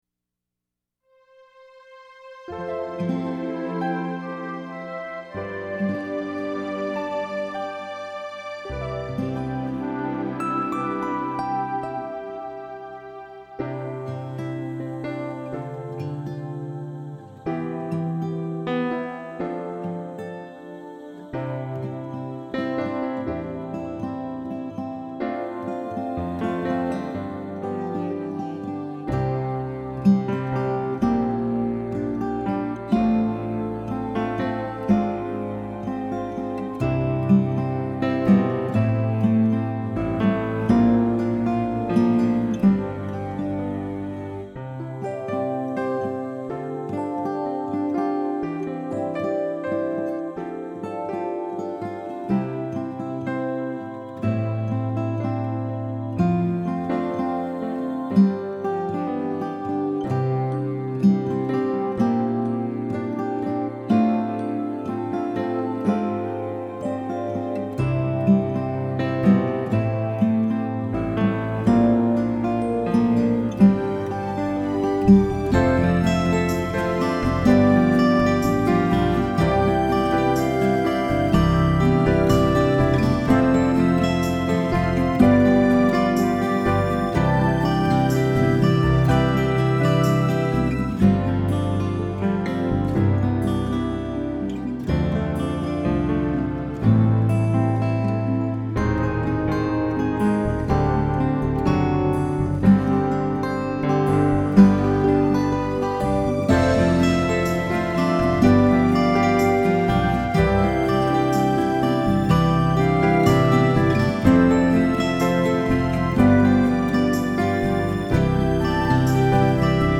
Below are some recent guitar mixes I’ve done:
my-dream-2-gtr-mix-3.mp3